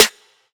Snare Stargazing.wav